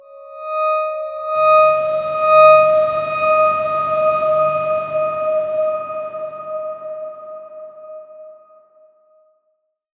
X_Darkswarm-D#5-mf.wav